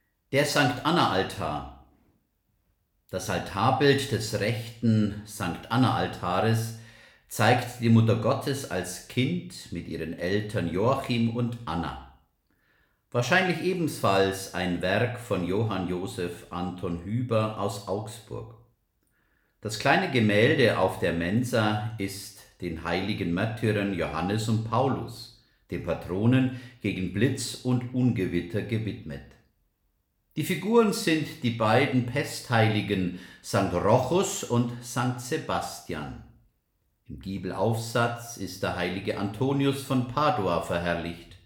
Kirchenführer Audioguide